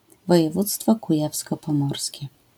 クヤヴィ＝ポモージェ県Kuyavian–Pomeranian Voivodeship (ポーランド語: województwo kujawsko-pomorskie [vɔjɛˈvut͡stfɔ kuˈjafskɔ pɔˈmɔrskʲɛ] (